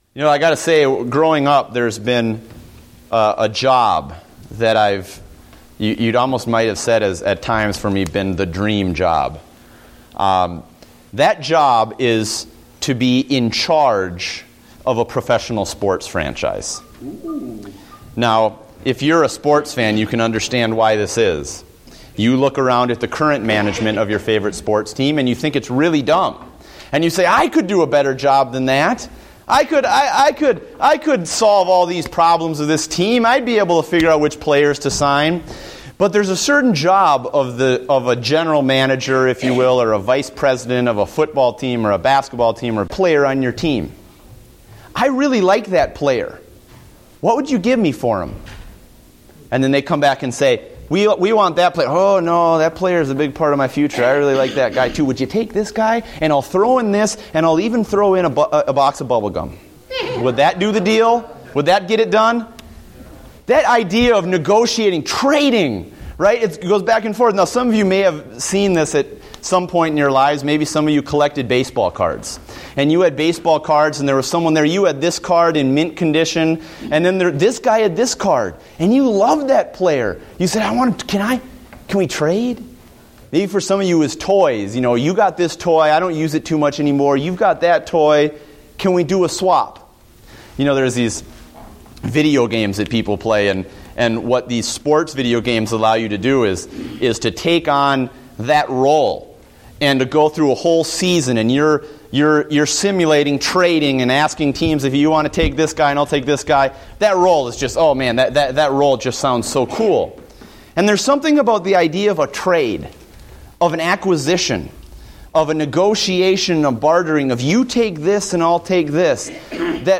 Date: June 15, 2014 (Adult Sunday School)